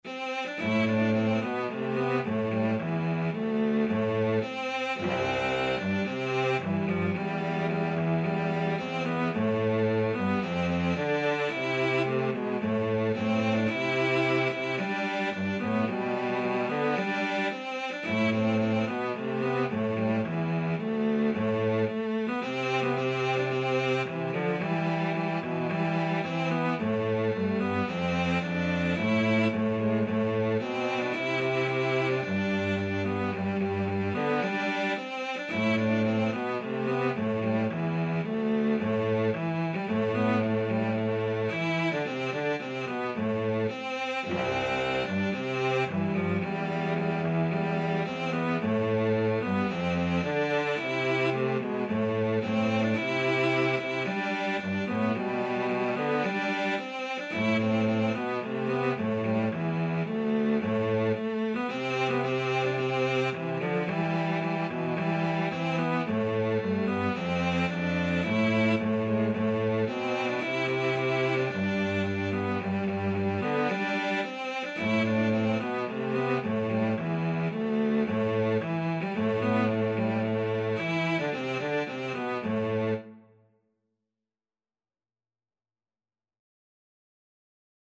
~ = 110 Allegro (View more music marked Allegro)